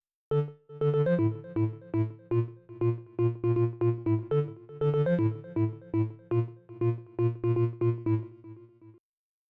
Organ Bass
a little bit snapppppier